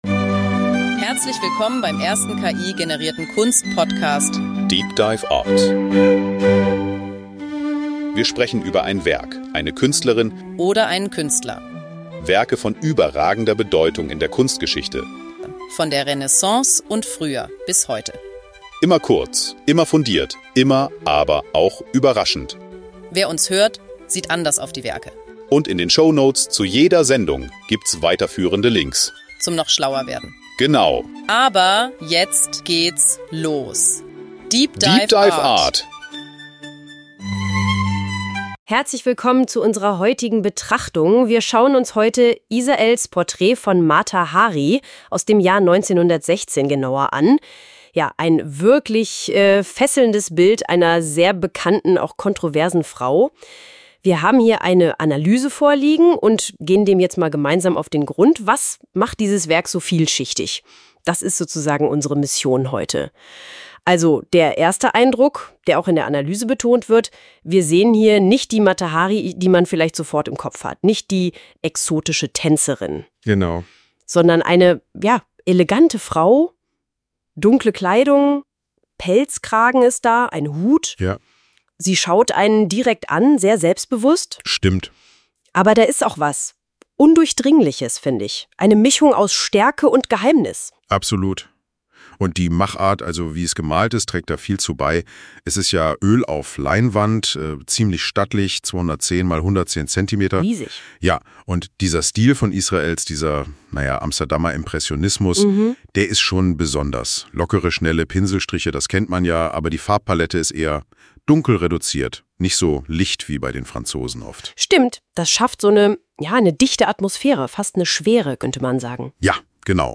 voll-ki-generierte Kunst-Podcast. Beginnend mit der Recherche über
die Analyse bis zur Interpretation. Die beiden Hosts, die Musik,